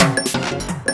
SI2 DRUMJAM.wav